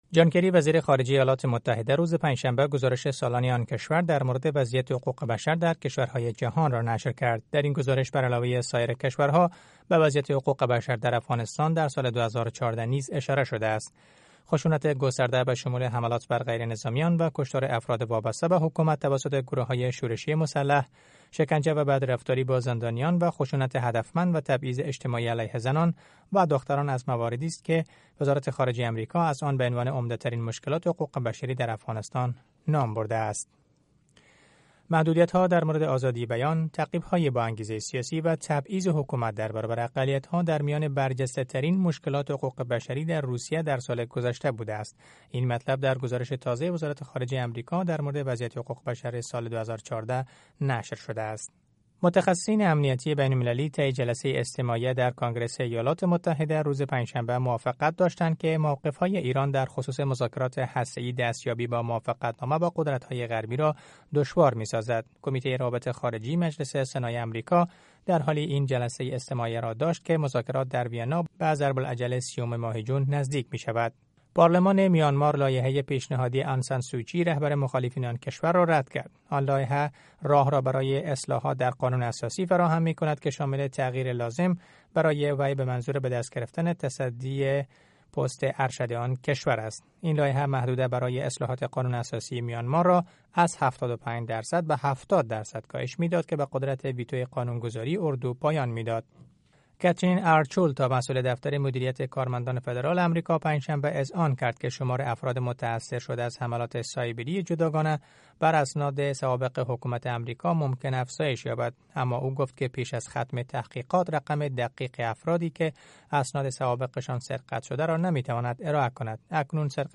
فشرده خبرهای افغانستان و جهان در سه دقیقه